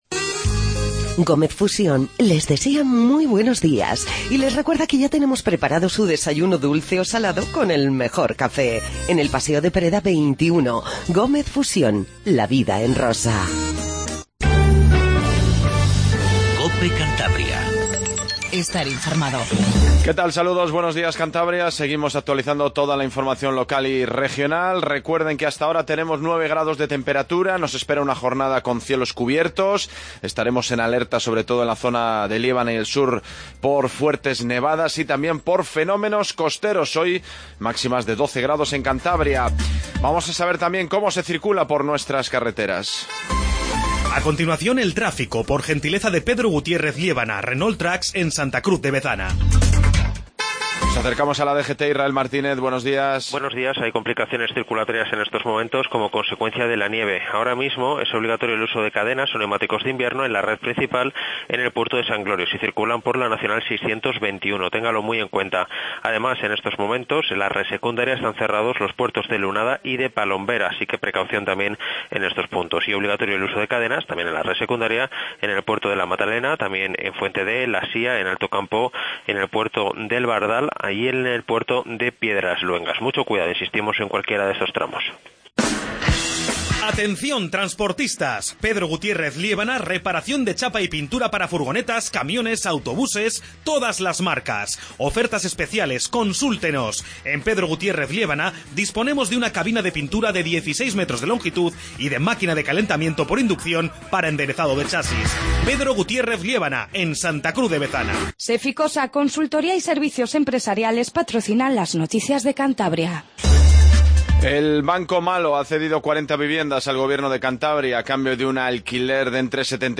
INFORMATIVO MATINAL 07:50 10 DE MARZO